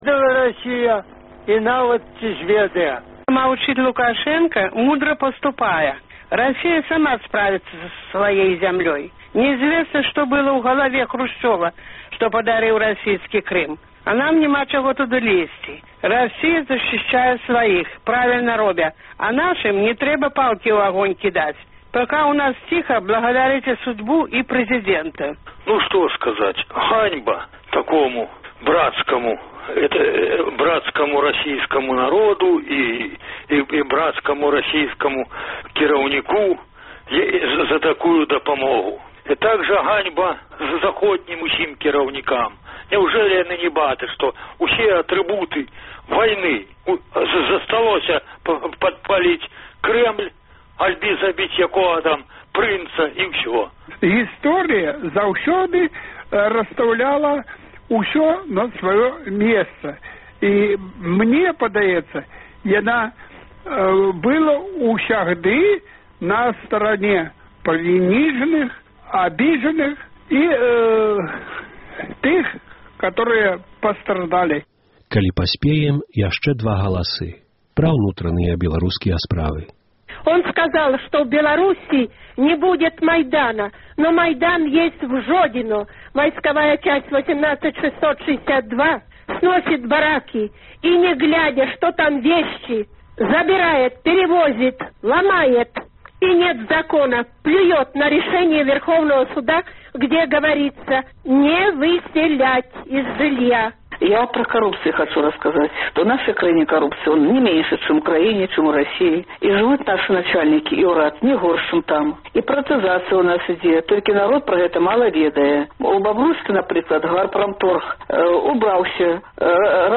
Гутаркі